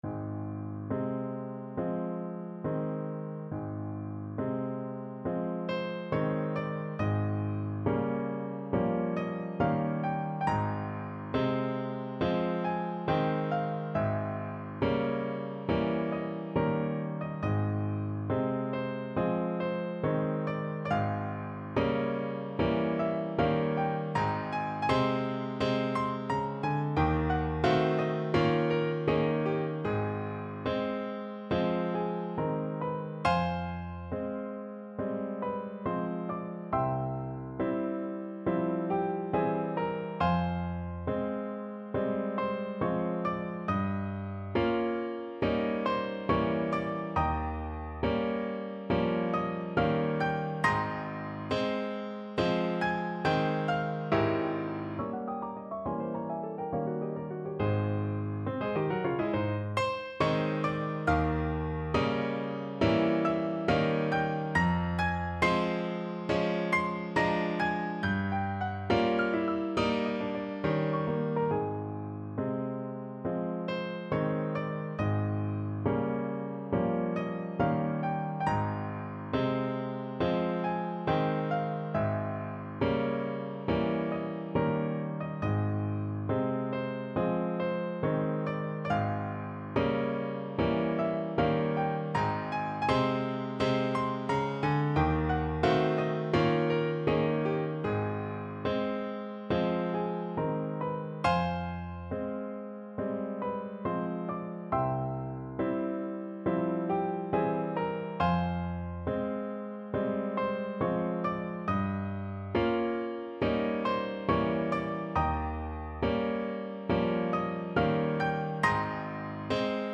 No parts available for this pieces as it is for solo piano.
4/4 (View more 4/4 Music)
Ab major (Sounding Pitch) (View more Ab major Music for Piano )
Andante non troppo con grazia =69
Piano  (View more Advanced Piano Music)
Classical (View more Classical Piano Music)